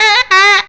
COOL SAX 15.wav